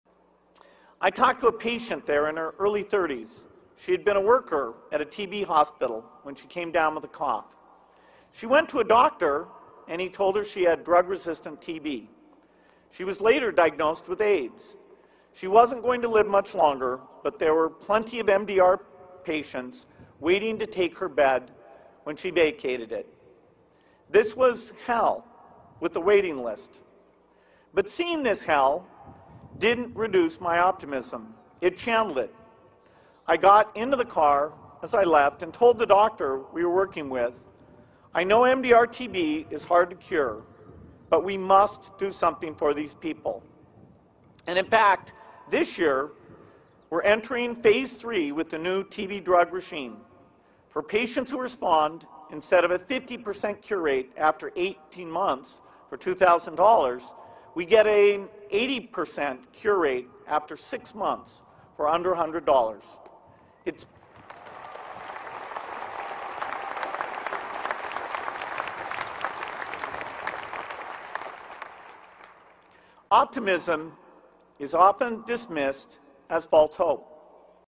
公众人物毕业演讲第28期:比尔盖茨夫妇于斯坦福大学(9) 听力文件下载—在线英语听力室